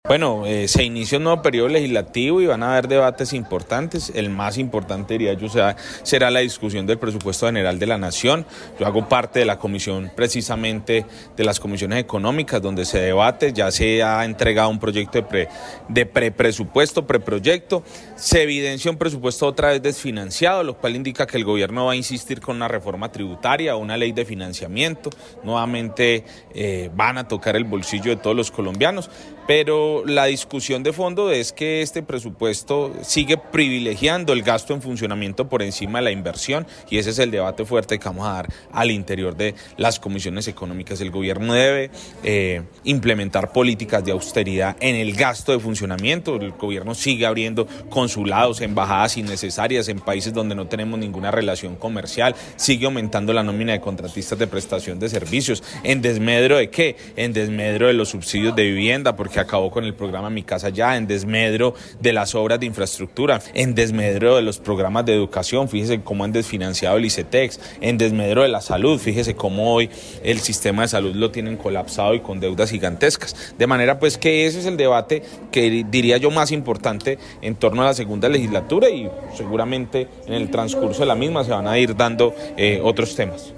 Representante a la cámara, John Edgar Pérez